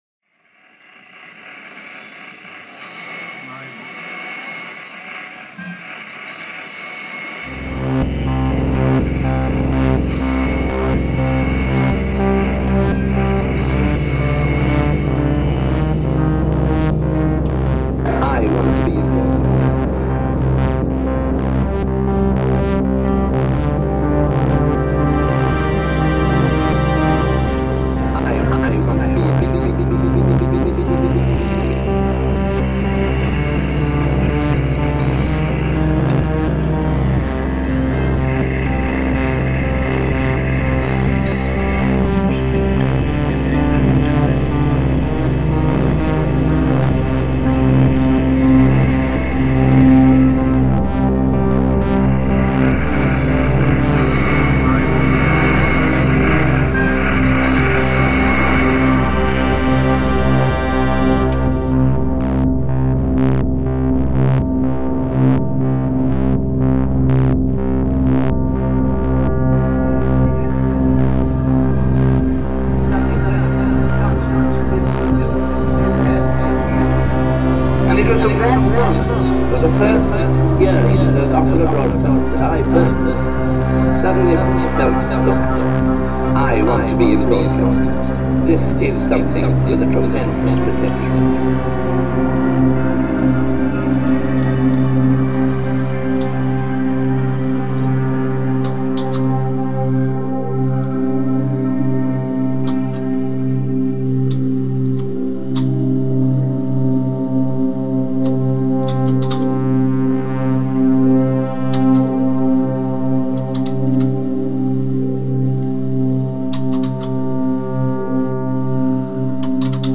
Science Museum exhibit reimagined by Cities and Memory.
As such, we've brought together sounds that signify science fiction - big, buzzing waves of synths - to package up sounds from the early history of broadcast communication.